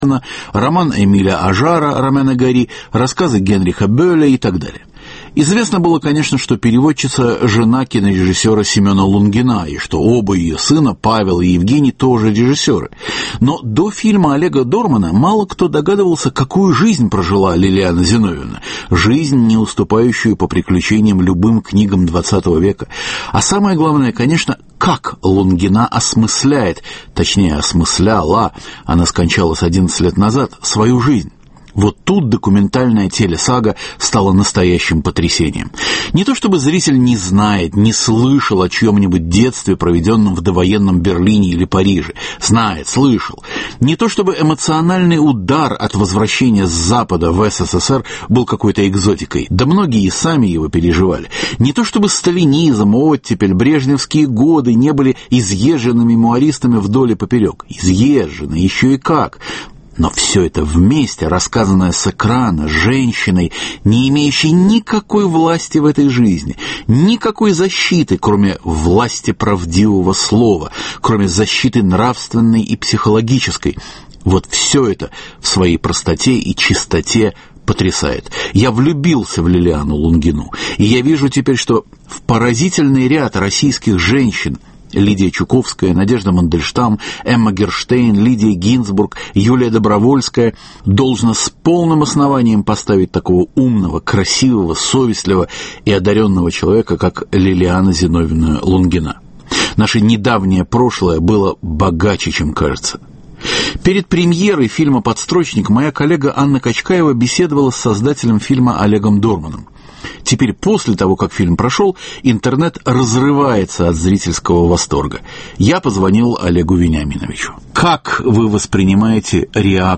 Документальный фильм Олега Дормана "Подстрочник" - разговор после премьеры.